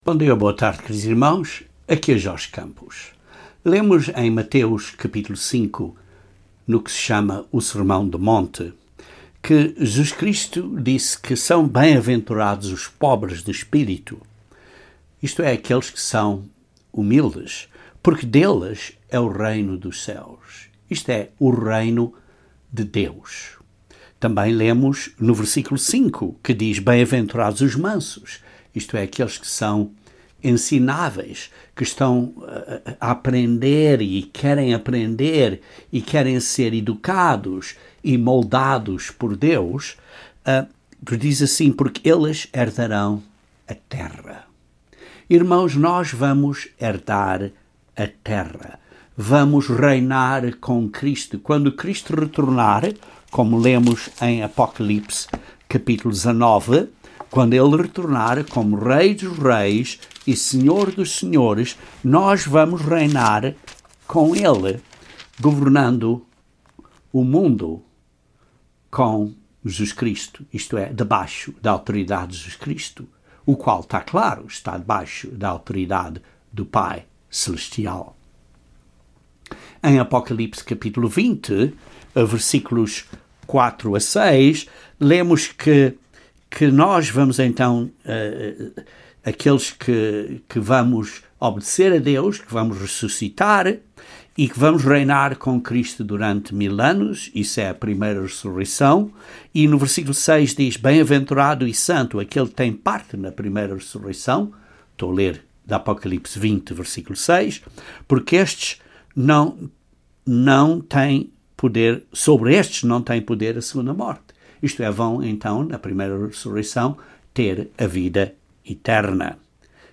Este sermão descreve alguns pontos de como nos podemos preparar, respondendo ao chamado para reinarmos com Cristo.